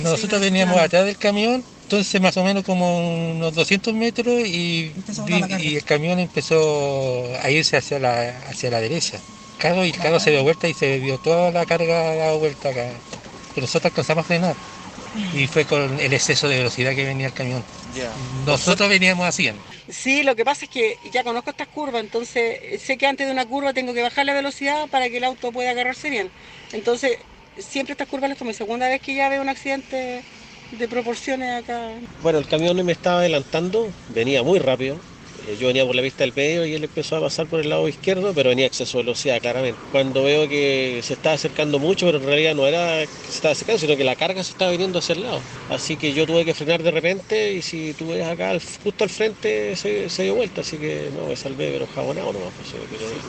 testigos.mp3